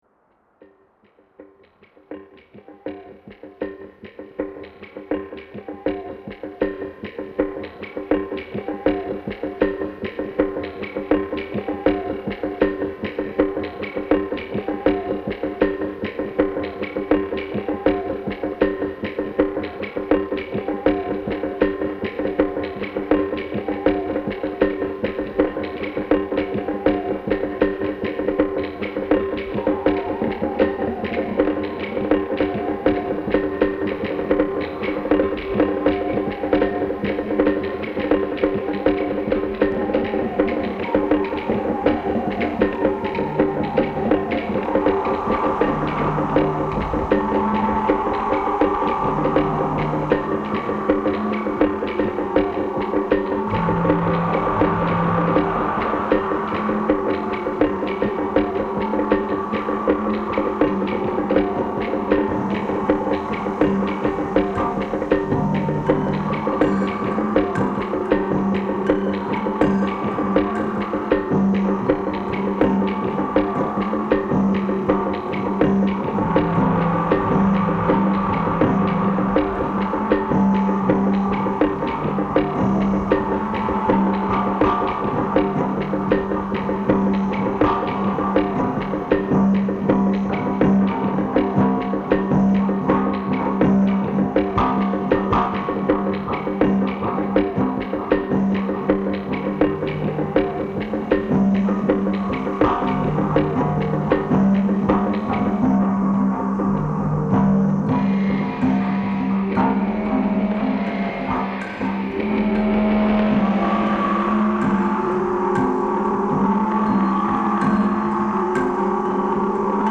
This piece began with the field recording: Bom war song and dance from Cameroon.
This recording felt both ancient and current, both in the same moment, yet a sonic event which felt distant.
The entire composition is derived from this single recording. The source material contained a strong rhythmic pattern which subtly shifted over time, revealing internal variations rather than static repetition.